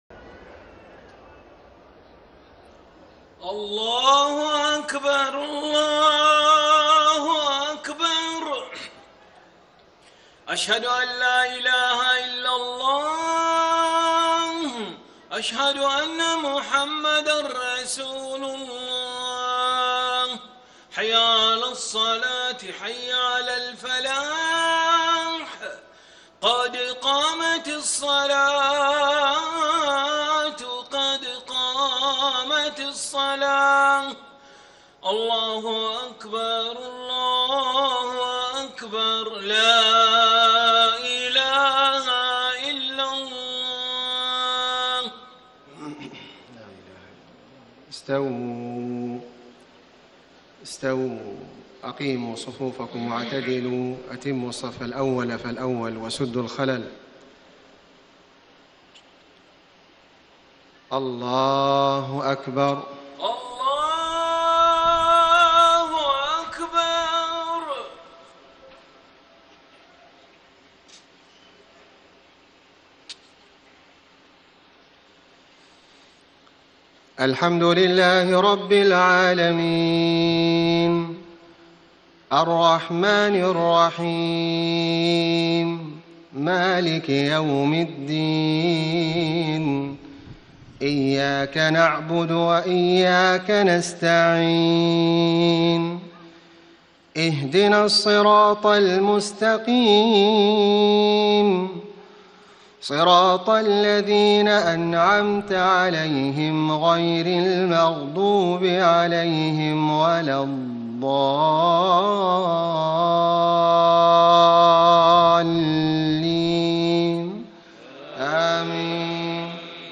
صلاة العشاء 5 - 7 - 1435هـ من سورة البقرة > 1435 🕋 > الفروض - تلاوات الحرمين